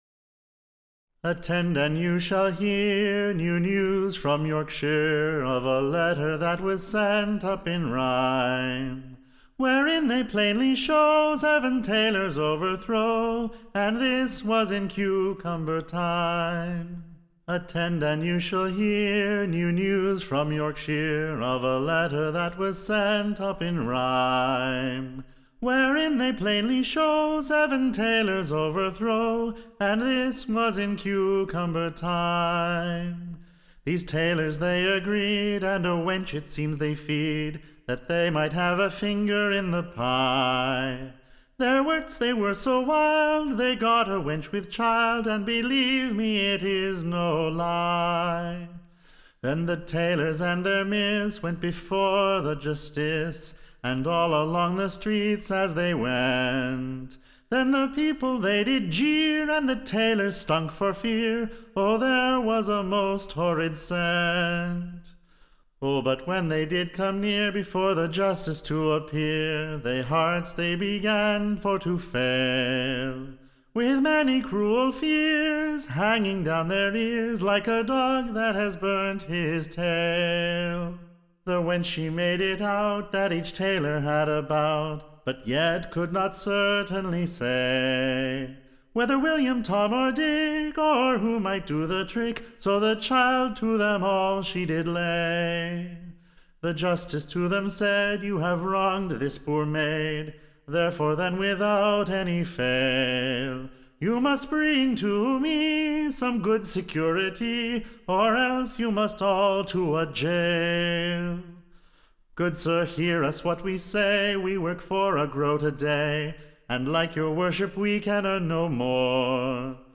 Recording Information Ballad Title The Lamentation of / Seven Journey men Taylors, / Being sent up in a letter from York=Shire, and writen in verse by a wit.